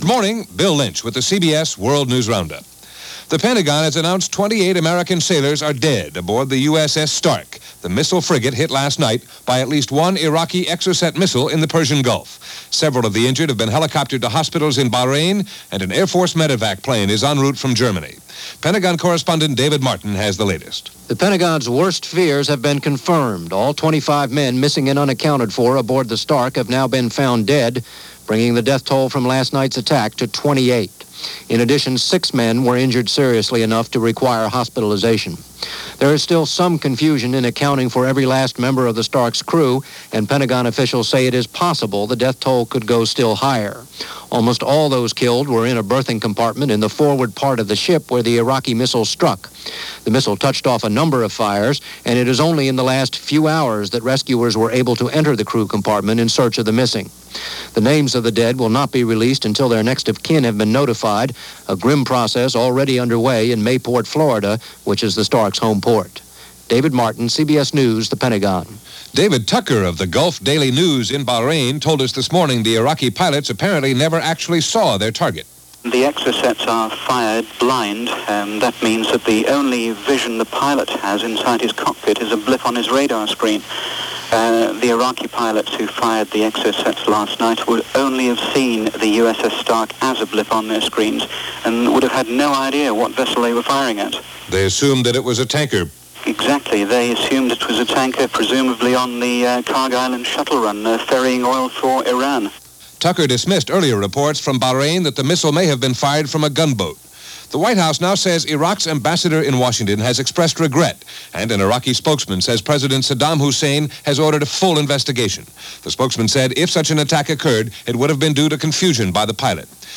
And that’s a little of what happened, this May 18th in 1987 as reported by the CBS World News Roundup.